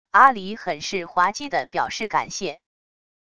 阿狸很是滑稽的表示感谢wav音频